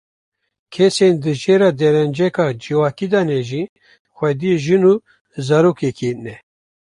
Pronunciado como (IPA)
/ʒɪn/